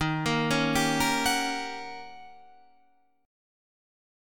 Eb7#9 Chord